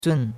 zun4.mp3